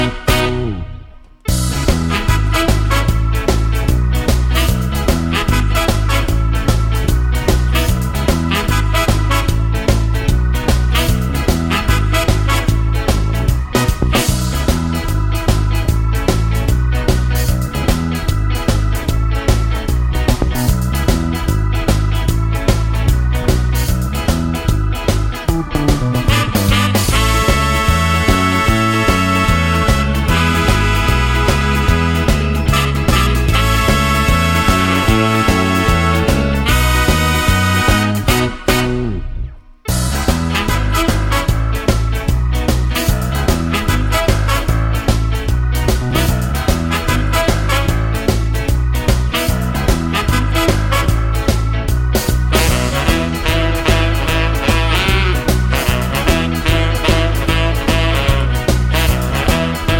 no Backing Vocals or harmonica Ska 2:37 Buy £1.50